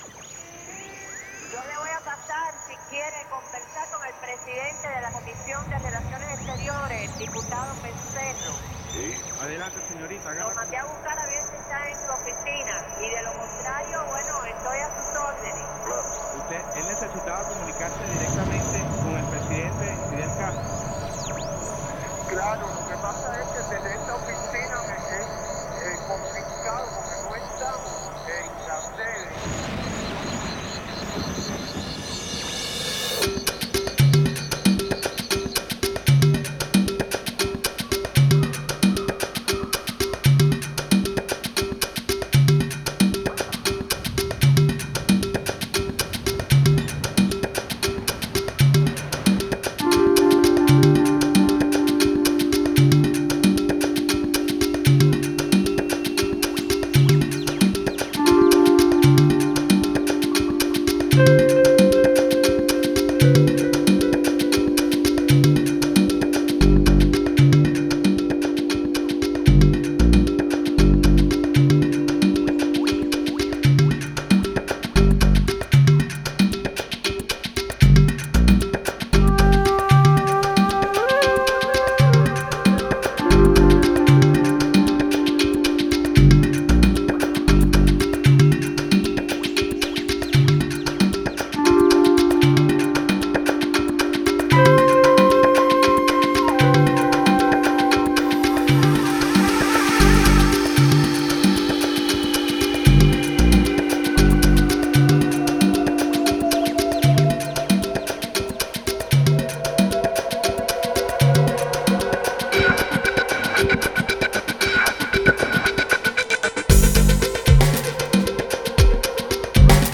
Genre: Downtempo, Chillout, World.